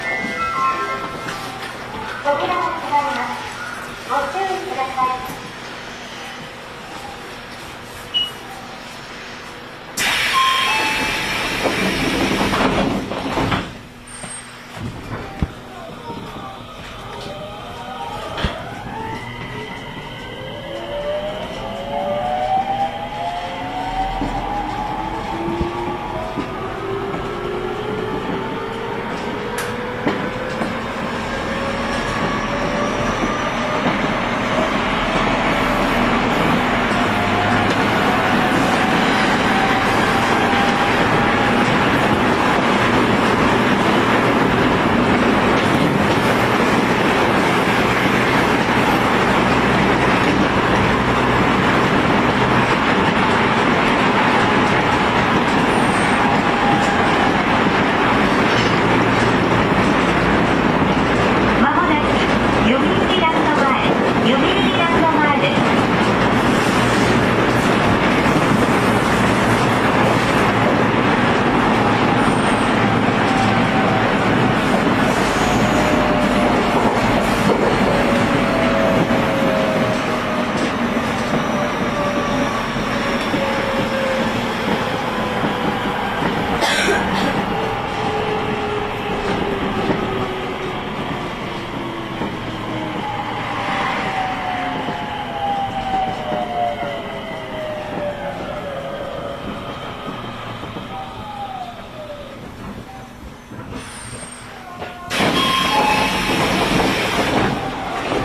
インバーター制御方式がGTOからIGBTに変更になるなど改善が図られています。
走行音
OE01 2000系 百合丘-読売ランド前 1:48 10/10 --